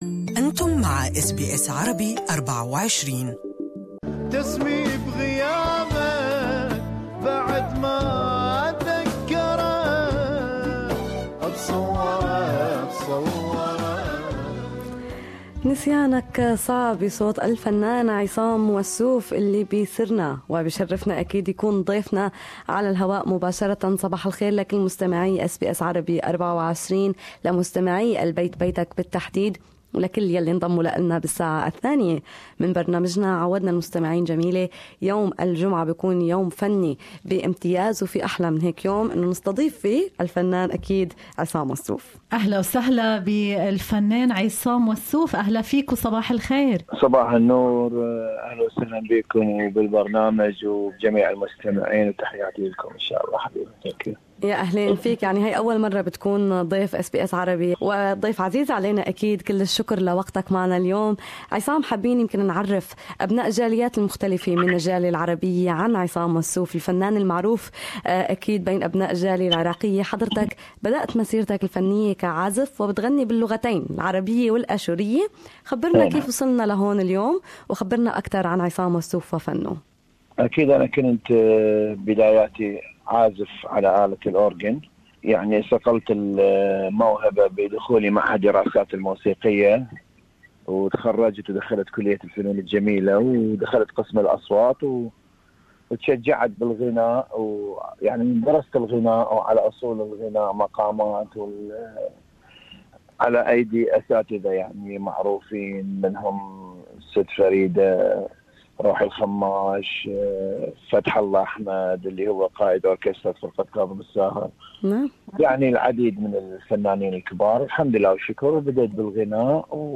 He has released ann album in 2017 and awaiting the release of another in 2018, He was interviewd live on SBS Arabic 24' Bayt Baytak.